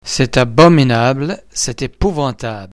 Multi-syllabic word starting with a vowel: The first syllable in the word that starts with a consonant will receive the stress.